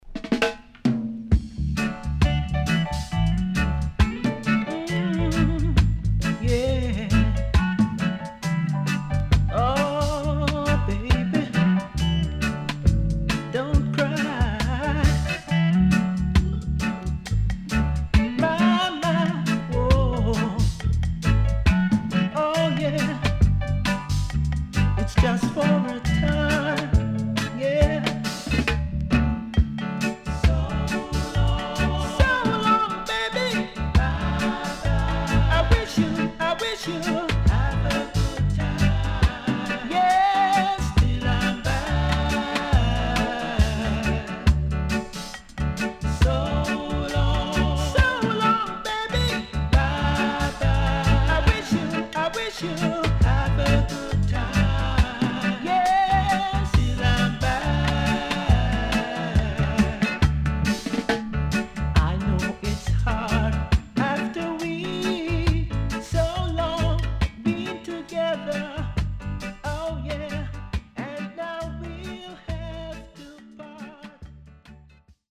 HOME > Back Order [VINTAGE LP]  >  STEPPER